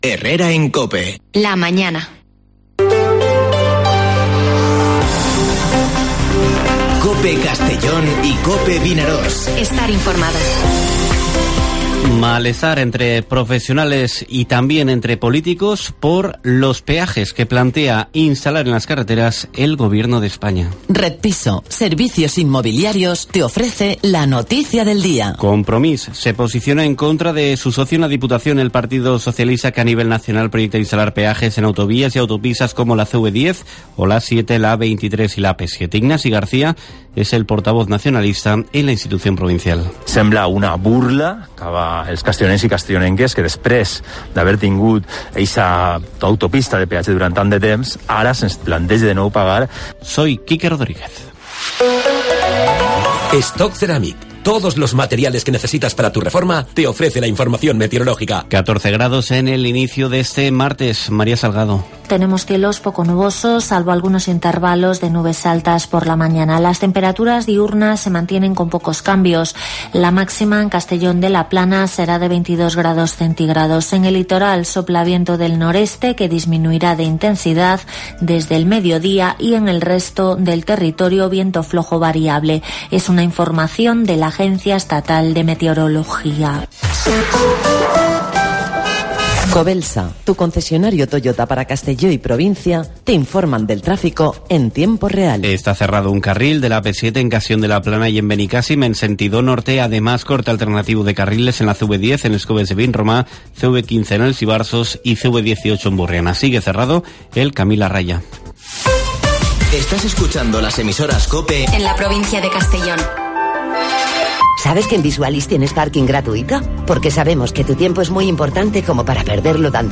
Informativo Herrera en COPE en la provincia de Castellón (26/10/2021)